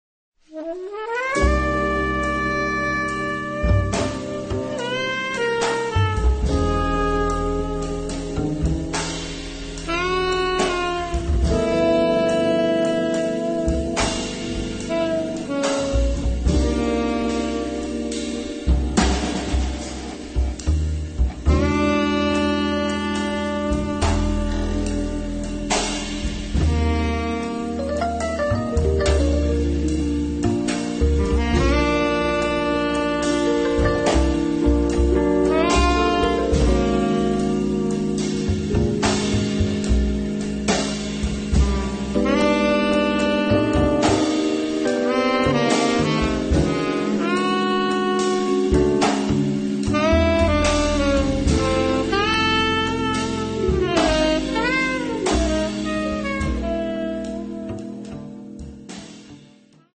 Fender Rhodes piano
tenor and soprano saxophone
acoustic and electric bass
drums
The music is there, close to us, alive and always exciting.